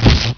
laser_hit.wav